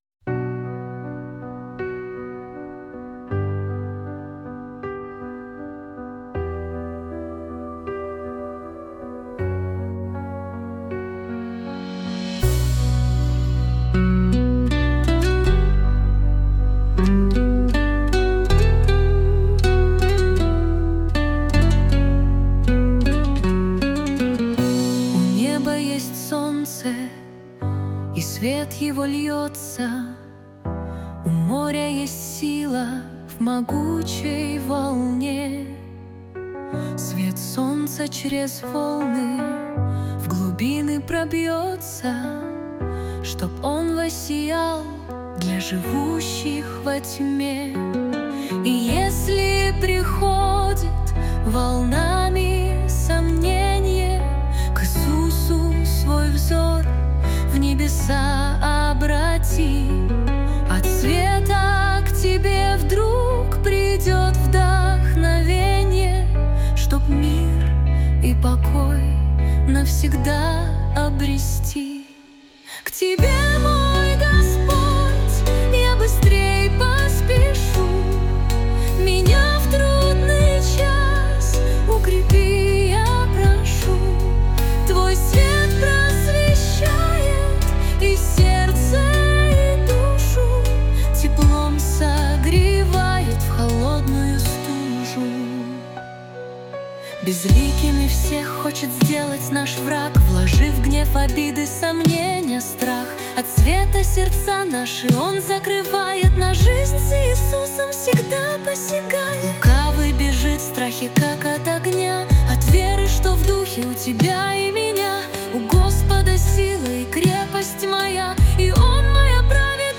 песня ai
154 просмотра 875 прослушиваний 36 скачиваний BPM: 79